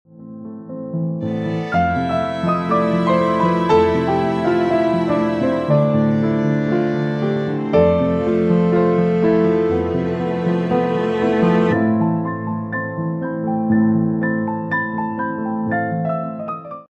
The fall spooky season has me wanting to write these dark, mysterious, mystical songs.